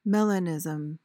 PRONUNCIATION:
(MEL-uh-niz-uhm)